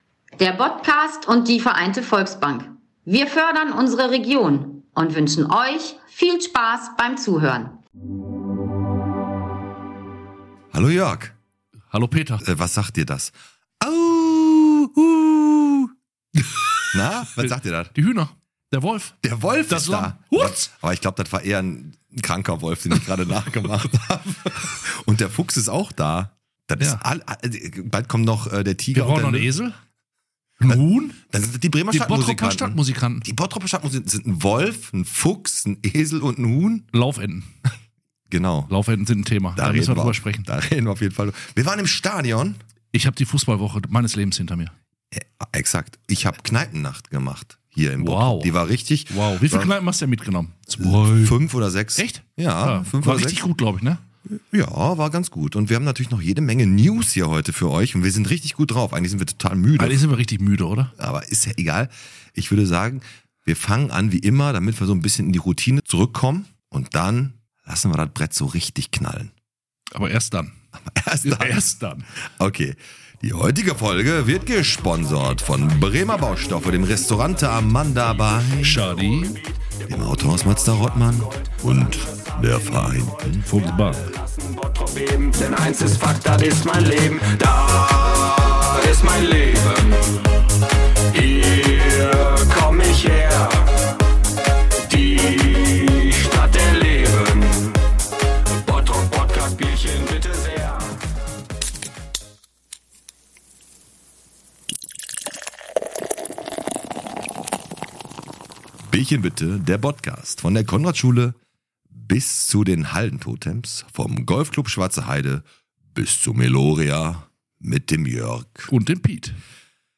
1 Light In The Dark - An Interview